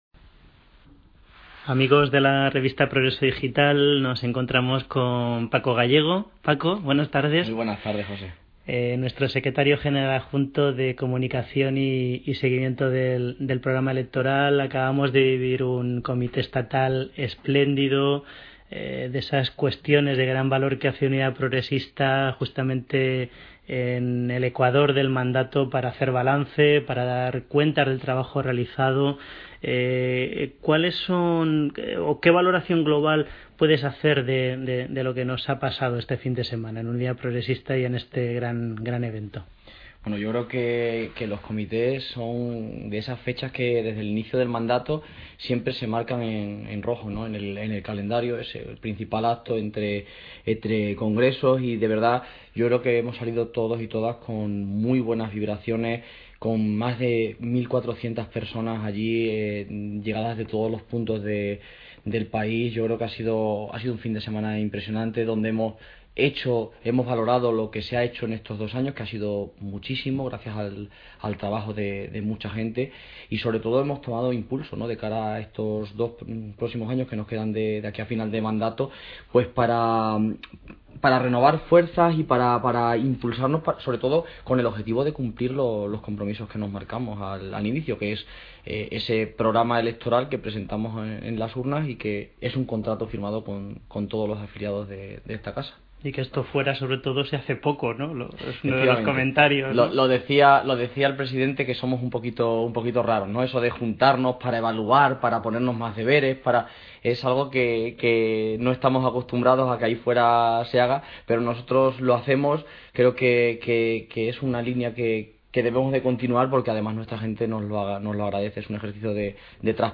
En esta entrevista sonora